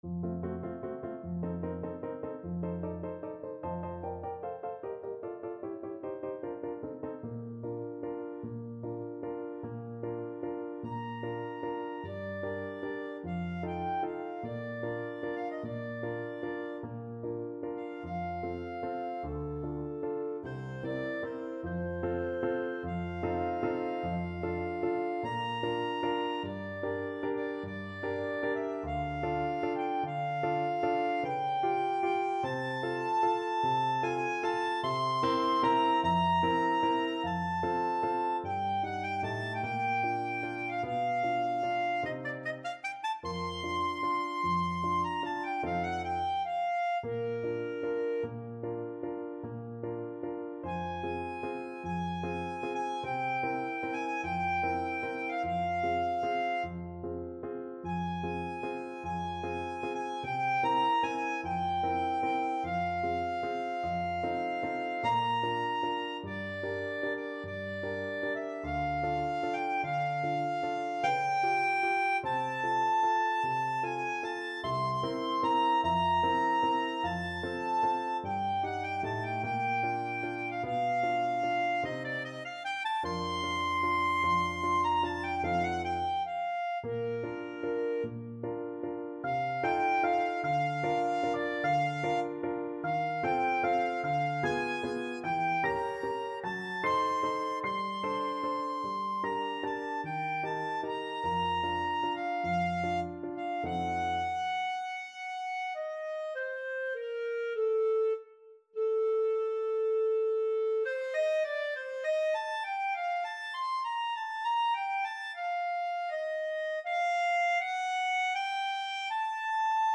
3/4 (View more 3/4 Music)
Adagio =50
Classical (View more Classical Clarinet Music)